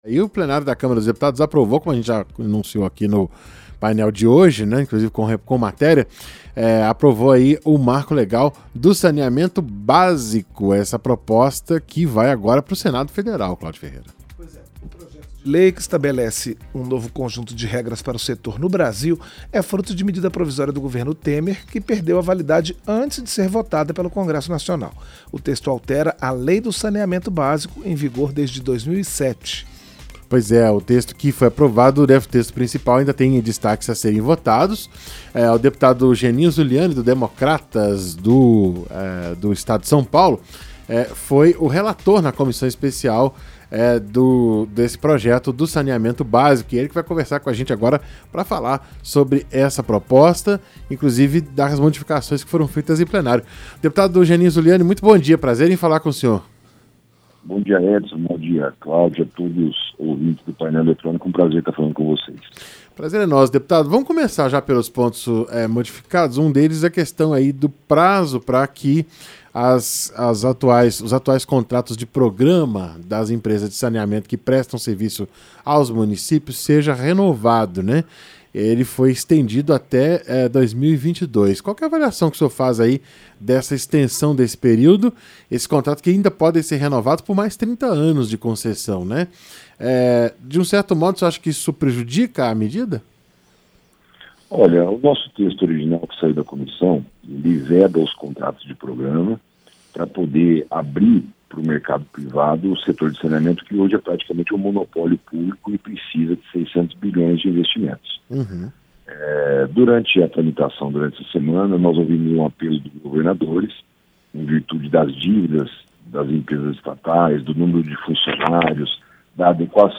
Entrevista - Geninho Zuliani (DEM-SP)